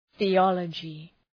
Shkrimi fonetik {ɵı’ɒlədʒı}